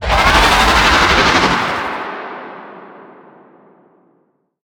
Sfx_creature_iceworm_vo_run_03.ogg